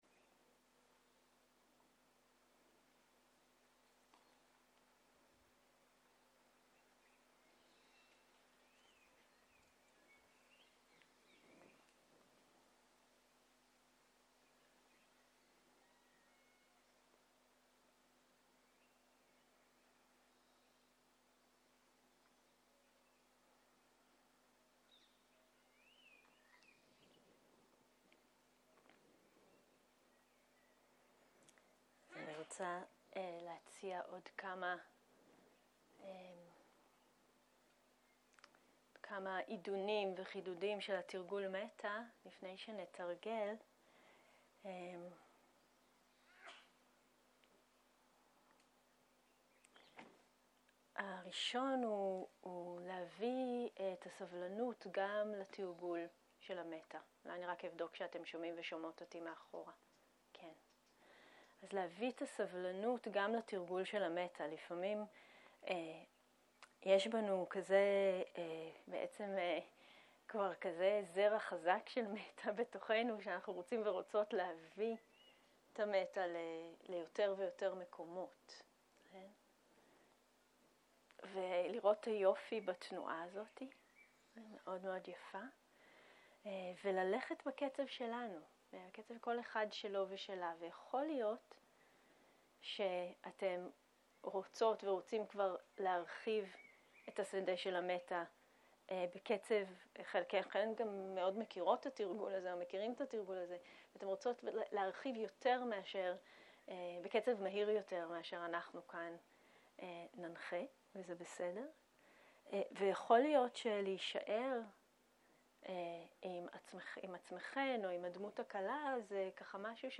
צהריים - מדיטציה מונחית - מטא לדמות המורכבת
סוג ההקלטה: מדיטציה מונחית